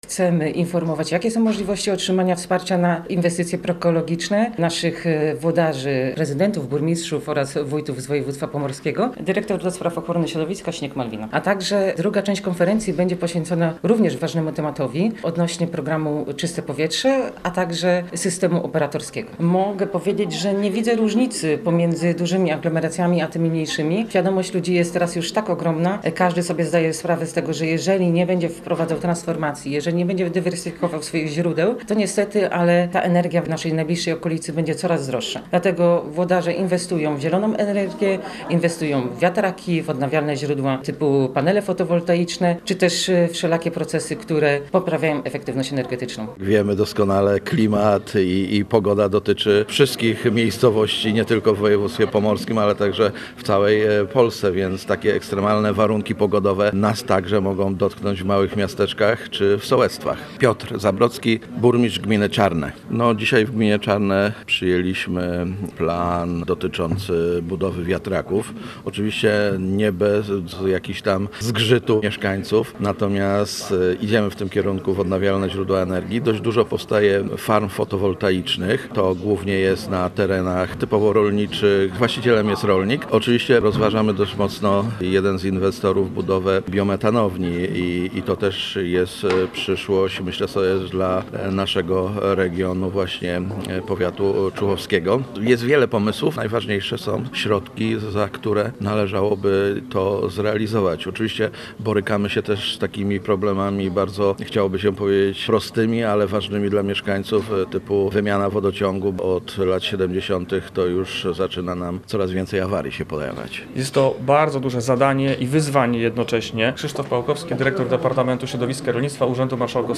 Piotr Zabrocki, burmistrz gminy Czarne, wskazał, że planowane są duże inwestycje związane z zieloną energią.
Posłuchaj materiału naszego reportera: https